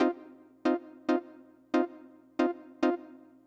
synth04.wav